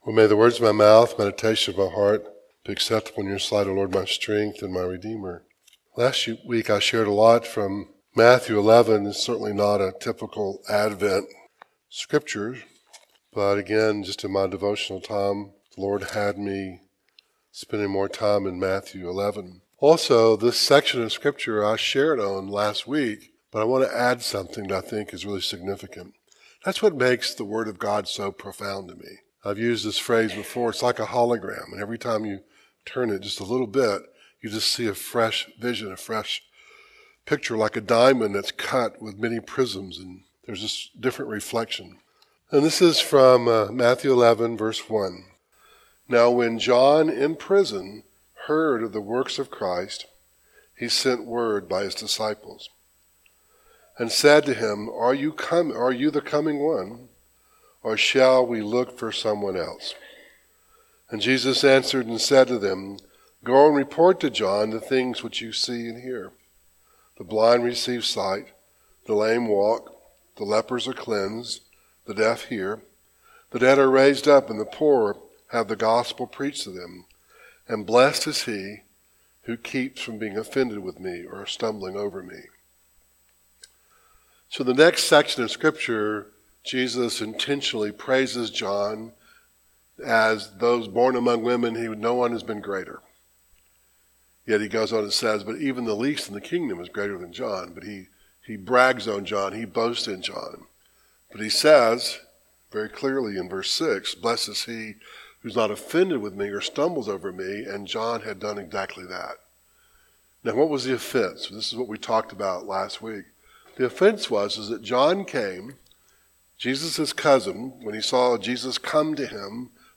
Matthew 11:1-6 Service Type: Devotional Matthew 11:1-6